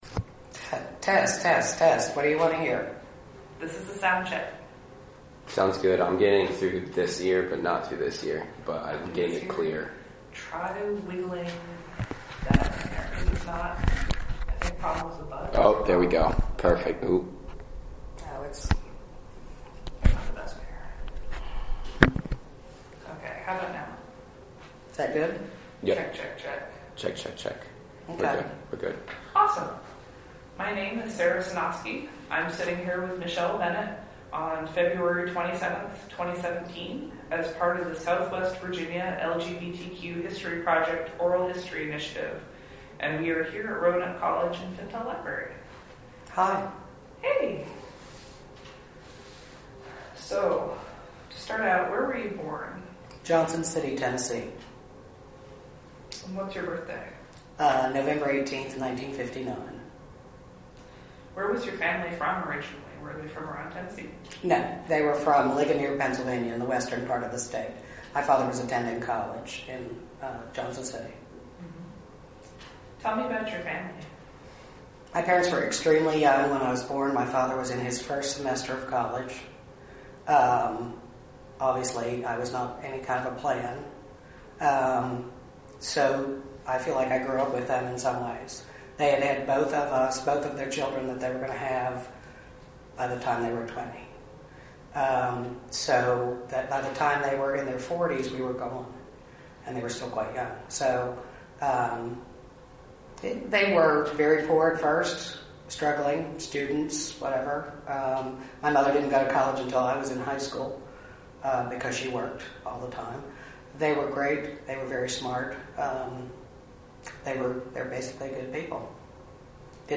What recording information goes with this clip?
Location: Fintel Library, Roanoke College